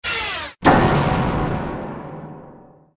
AA_drop_piano_miss.ogg